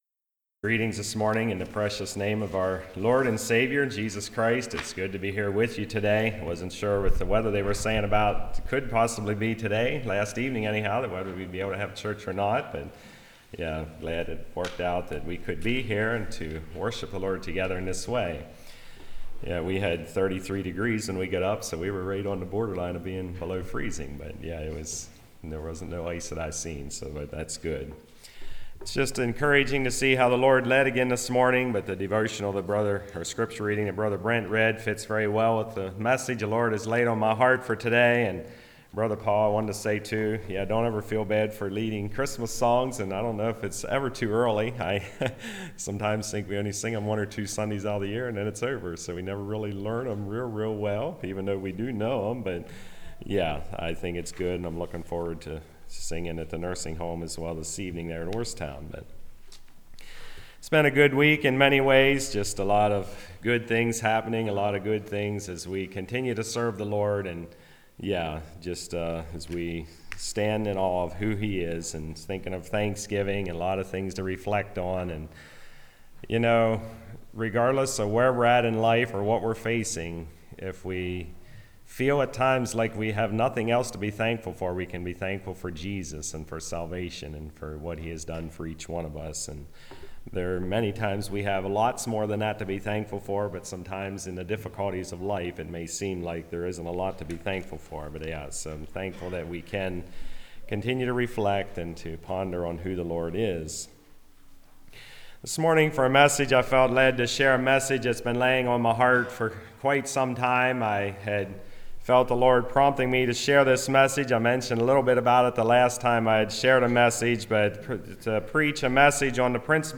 Passage: Ephesians 4:1-16 Service Type: Message